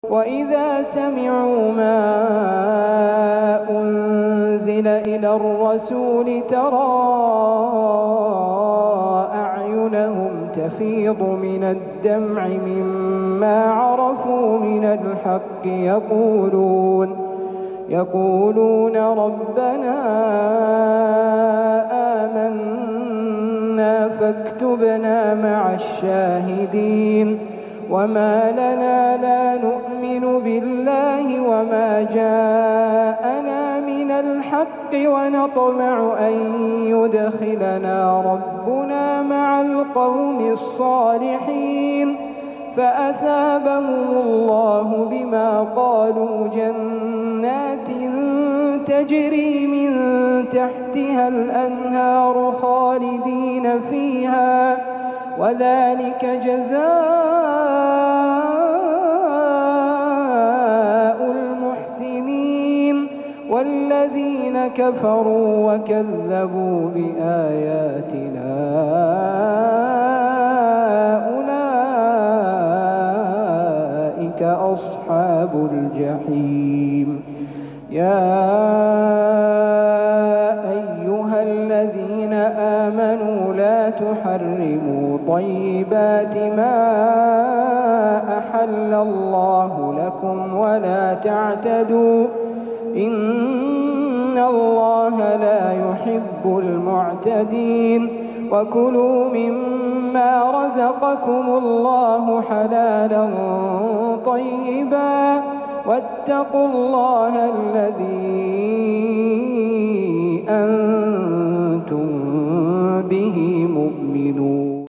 Reciter